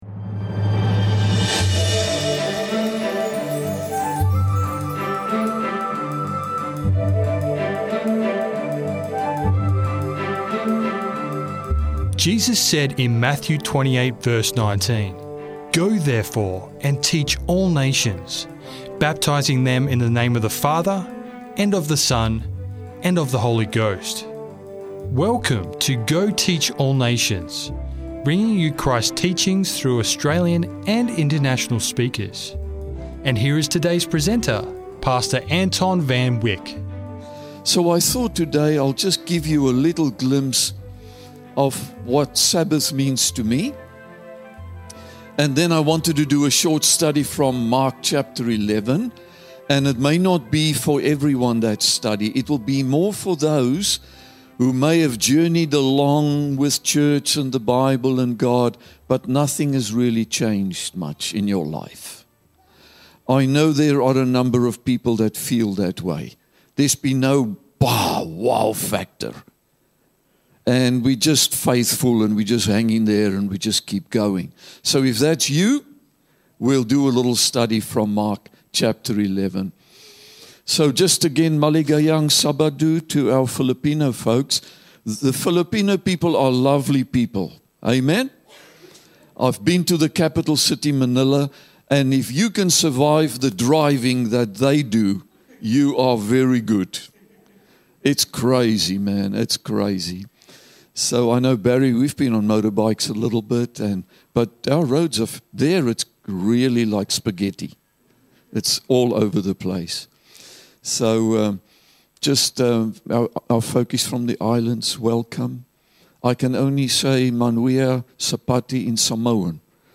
Sermon Audio: Go Teach All Nations What is Wrong With Lush and Leafy?